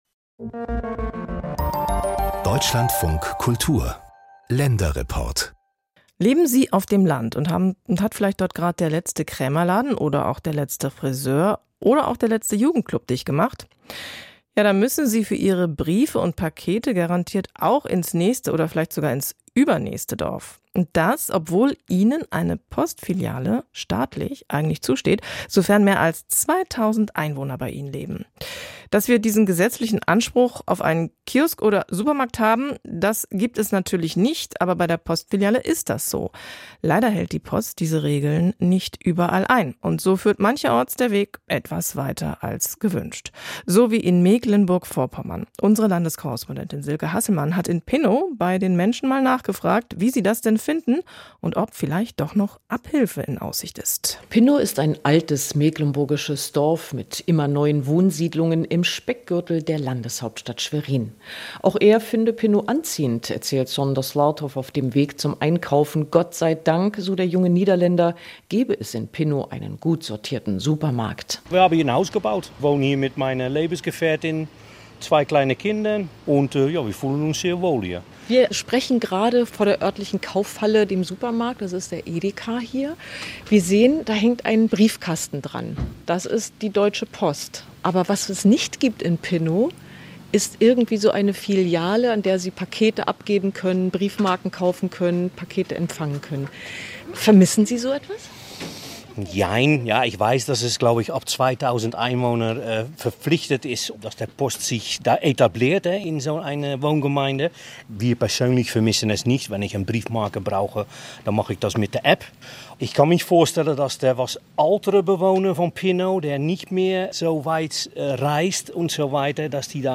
Der Länderreport von Deutschlandfunk (Deutschlandradio) hat aktuell zum Thema Postfilialen in Mecklenburg-Vorpommern berichtet. Pinnower Einwohner und Bürgermeister Günter Tiroux wurden interviewt.